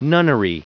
Prononciation du mot nunnery en anglais (fichier audio)